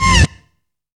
SAX FLYBY.wav